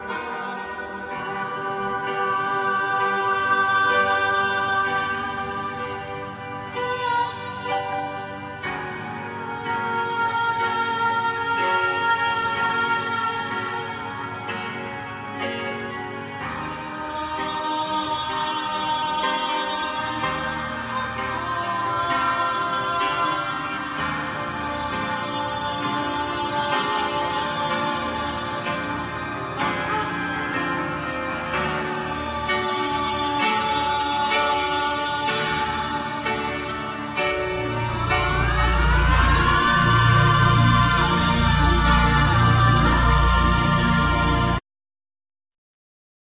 Keyboards
Guitar,Flute
Bass guitar
Drums